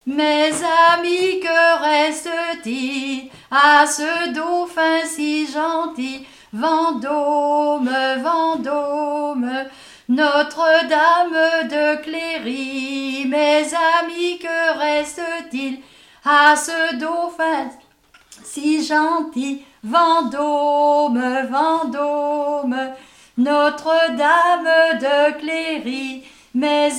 Mémoires et Patrimoines vivants - RaddO est une base de données d'archives iconographiques et sonores.
Chants brefs - Appelant
Pièce musicale inédite